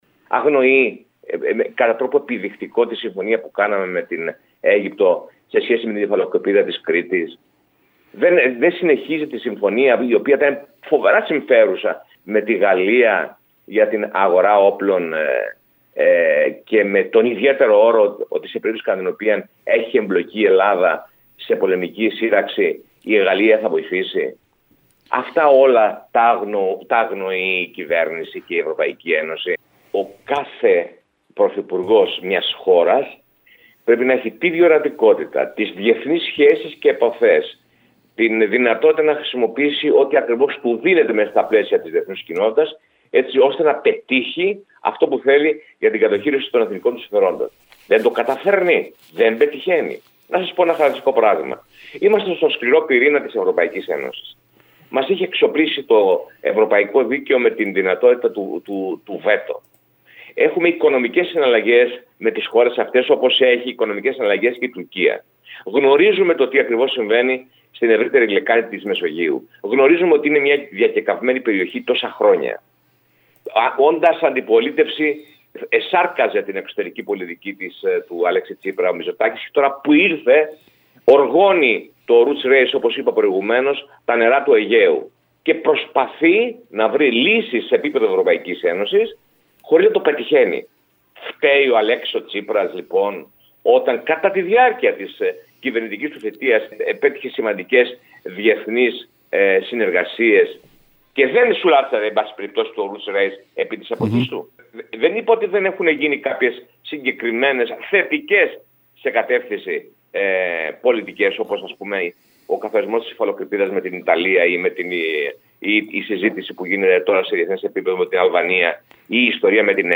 Την απόφαση αυτή σχολιάζουν μιλώντας σήμερα στην ΕΡΑ ΚΕΡΚΥΡΑΣ οι βουλευτές Στέφανος Γκίκας και Αλέκος Αυλωνίτης.